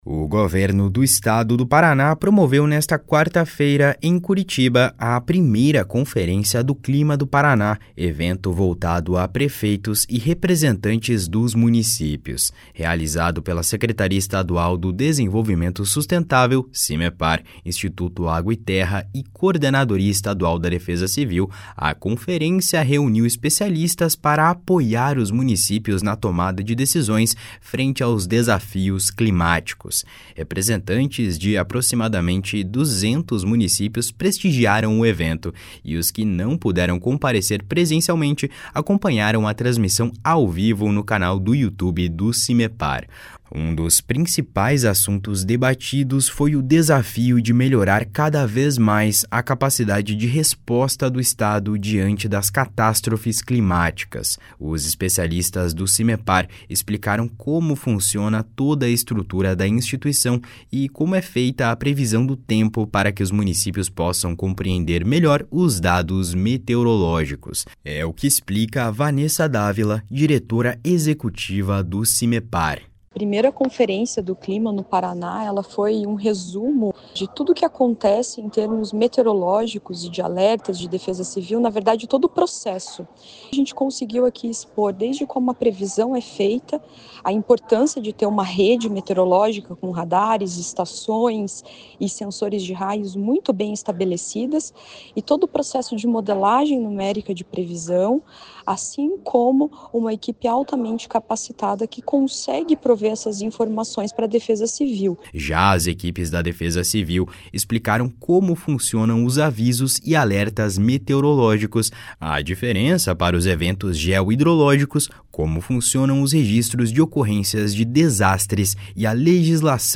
O evento também trouxe detalhes do laudo técnico entregue nesta semana pelo Simepar para o Governo do Paraná sobre os três tornados que atingiram o estado no dia 7 de novembro. (Repórter: